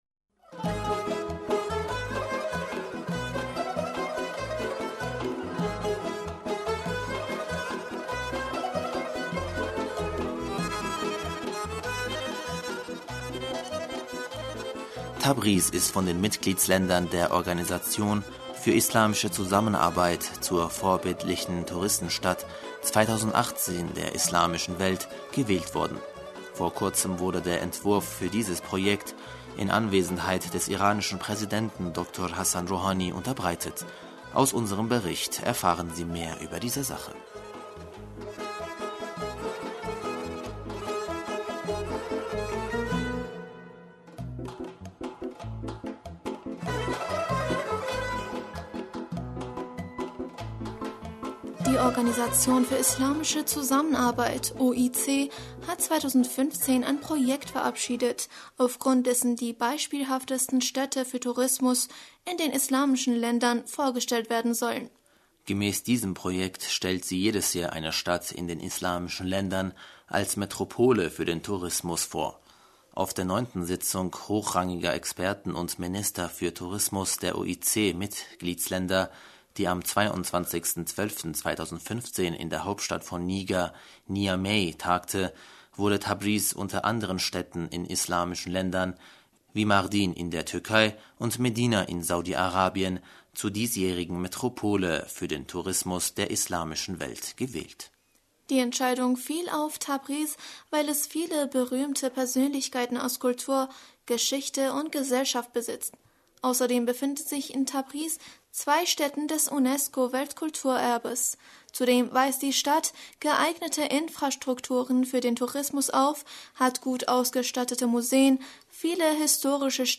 Aus unserem Bericht erfahren sie mehr über die Sache.